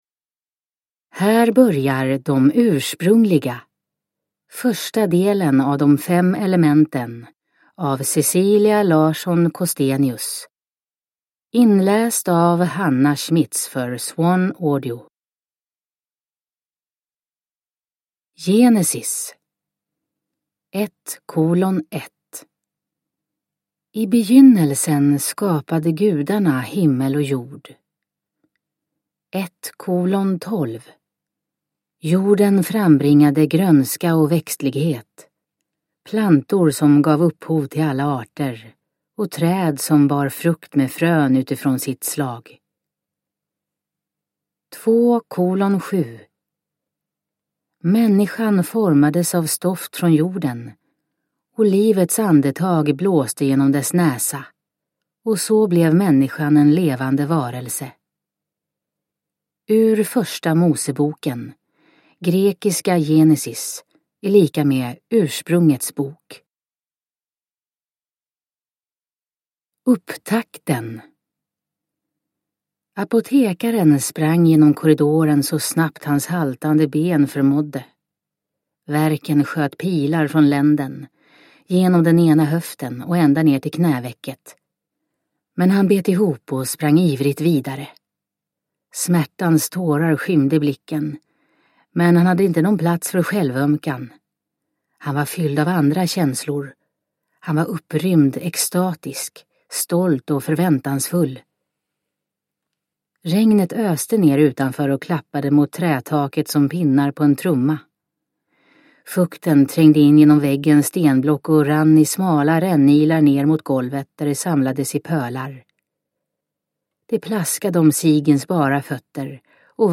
De Ursprungliga – Ljudbok